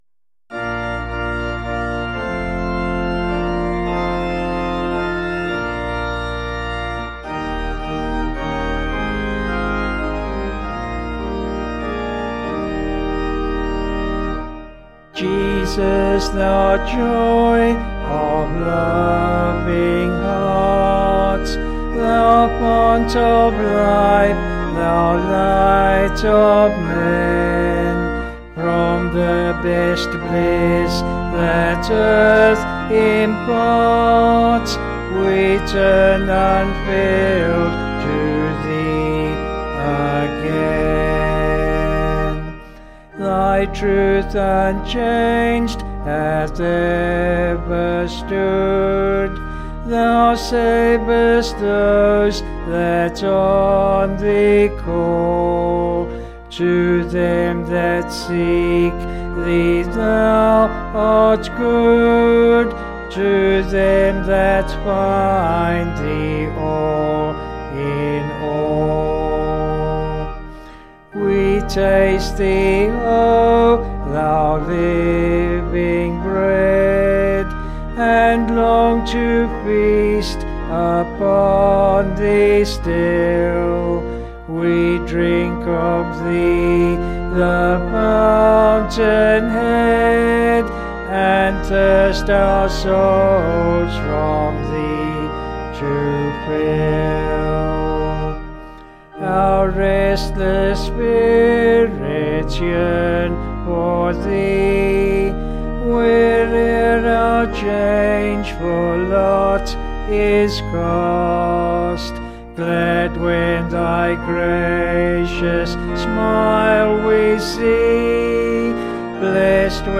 Organ MP3
Vocals and Organ   264.7kb